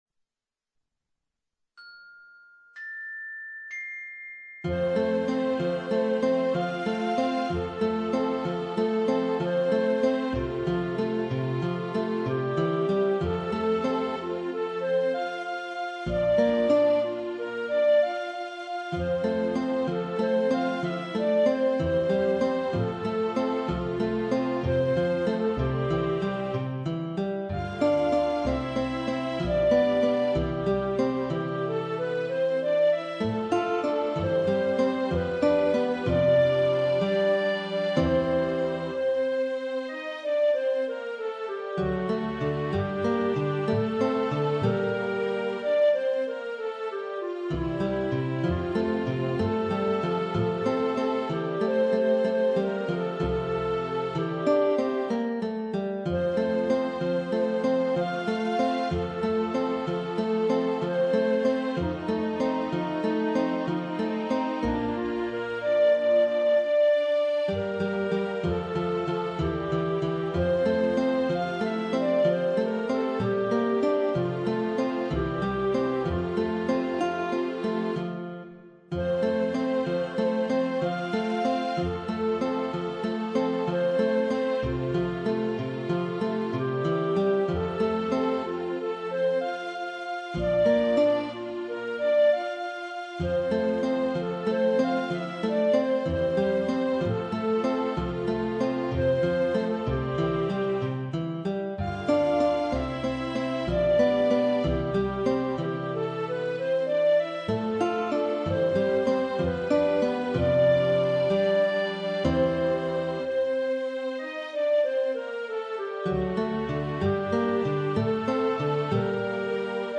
Una melodia deliziosa, semplice, infantile. Si tratta dell'Andante dalla Sonatina n. 1 op. 36 di Muzio Clementi per pianoforte, qui proposta per flauto in versione didattica.